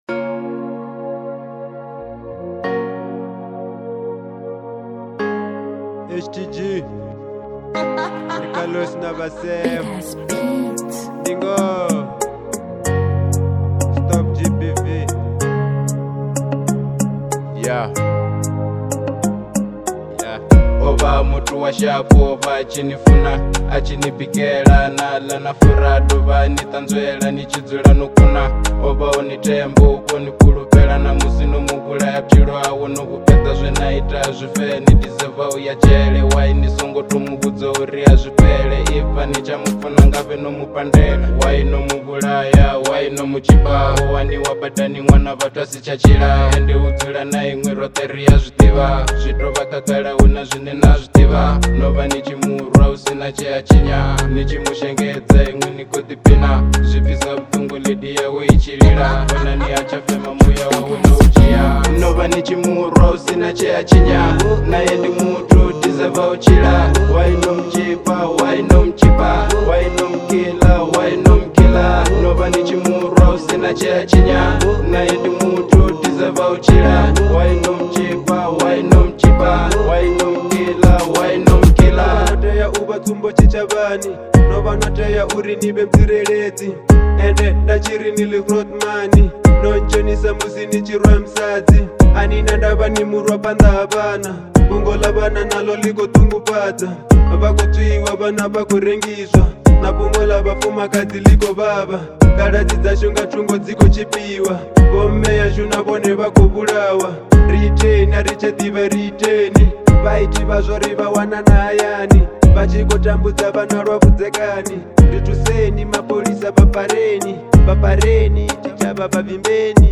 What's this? Genre : Venrap